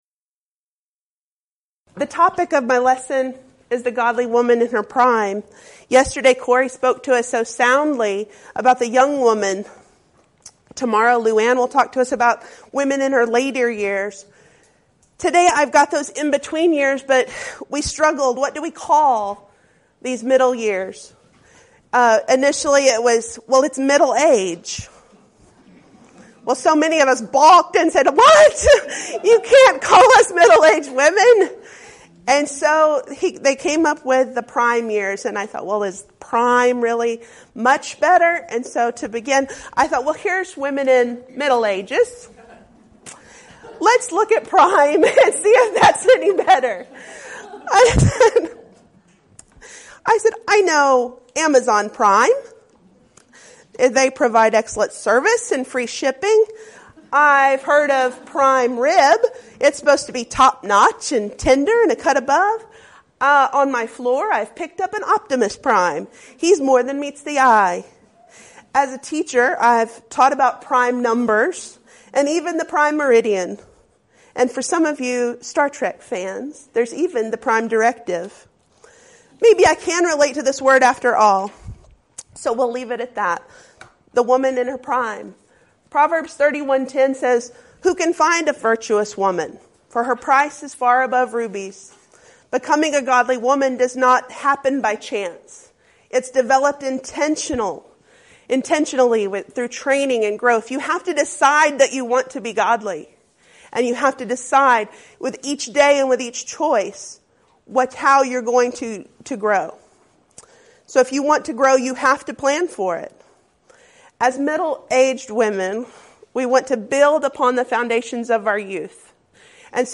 Event: 13th Annual Schertz Lectures
Ladies Sessions